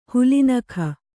♪ huli nakha